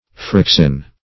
fraxin \frax"in\, n. [From Fraxinus.]